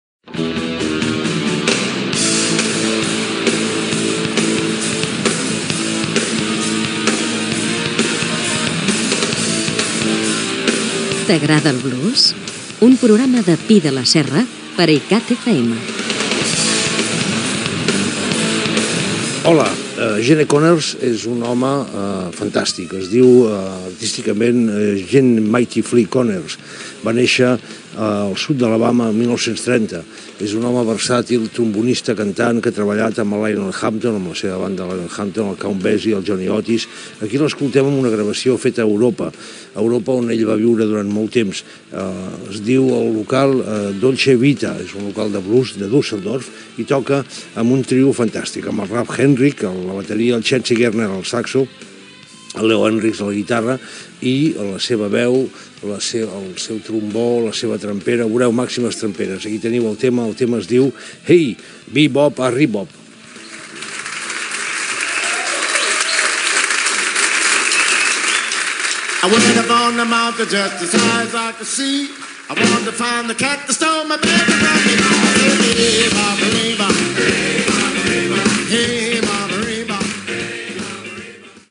Careta del programa, presentació d'un tema musical